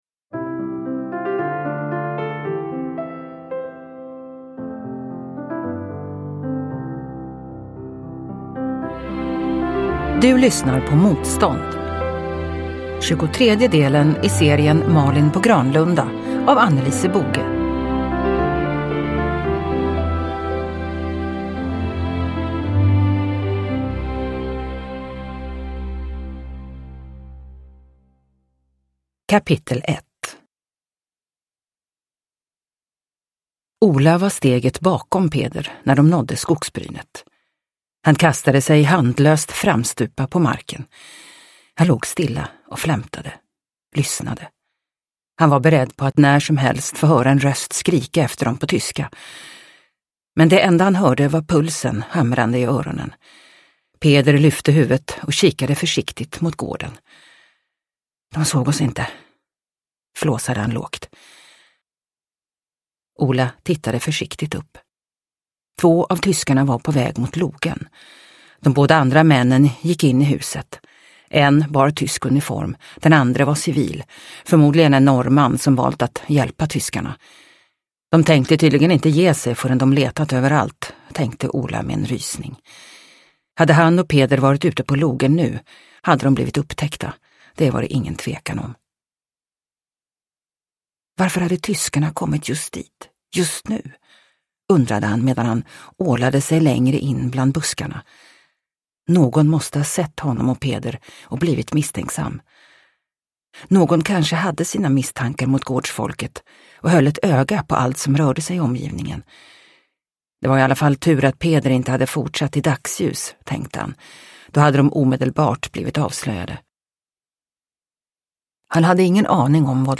Motstånd – Ljudbok – Laddas ner